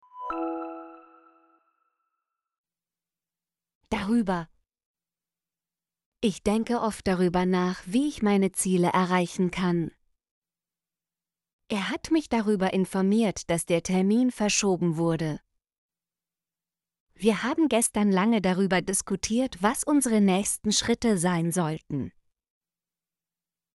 darüber - Example Sentences & Pronunciation, German Frequency List